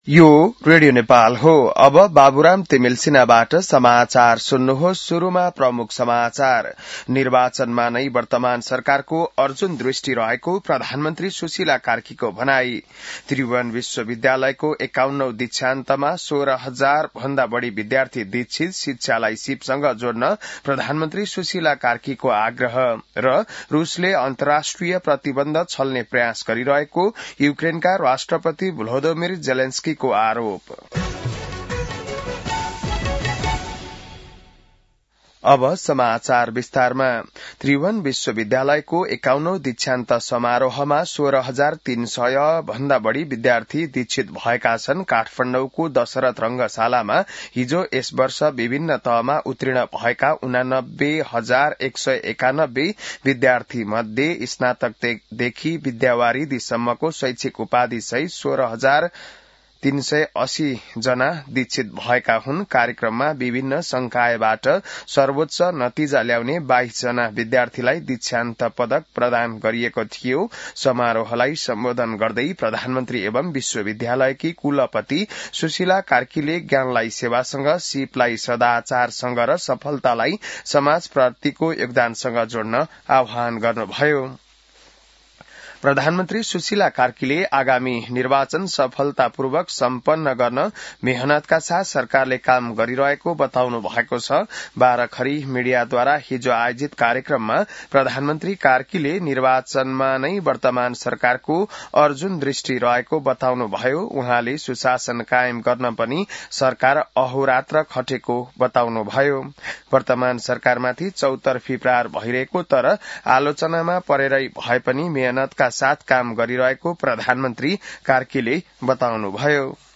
बिहान ९ बजेको नेपाली समाचार : ११ पुष , २०८२